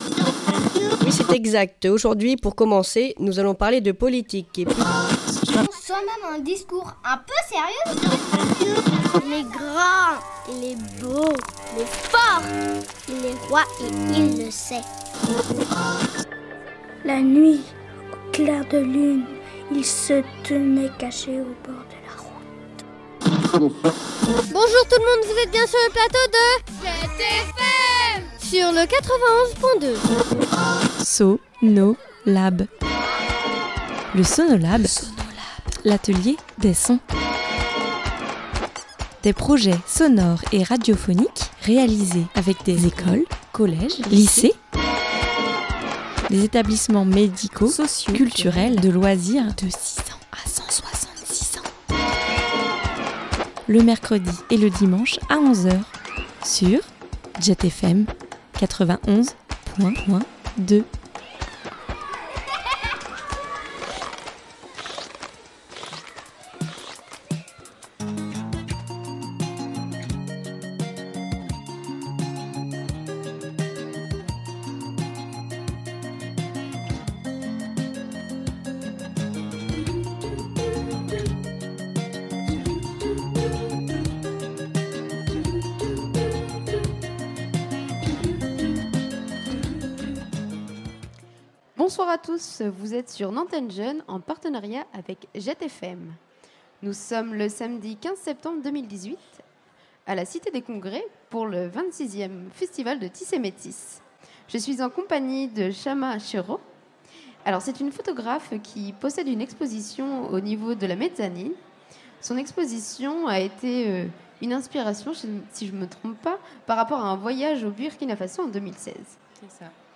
Dans cette deuxième partie d’émission consacrée à Tissé Métisse, on vous propose 2 nouveaux plateaux réalisés en public lors de la fête de Tissé Métisse qui a eu lieu le 15 décembre dernier à la cité des Congrès de Nantes.